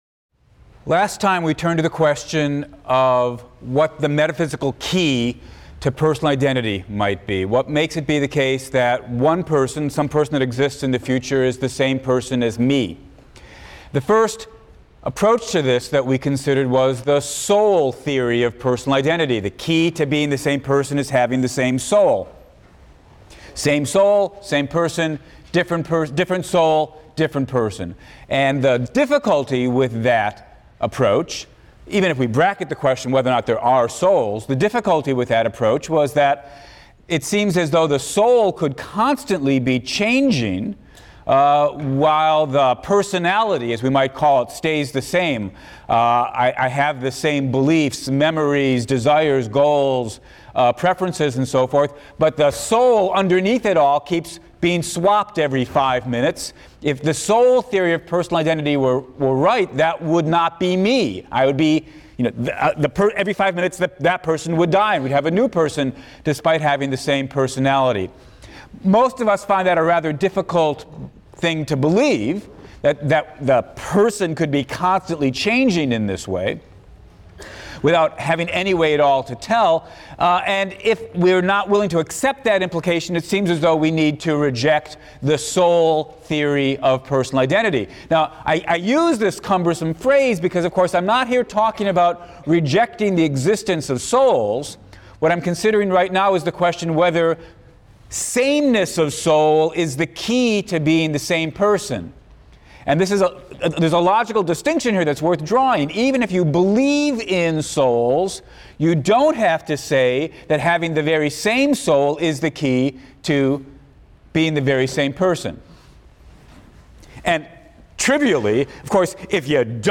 PHIL 176 - Lecture 11 - Personal Identity, Part II: The Body Theory and the Personality Theory | Open Yale Courses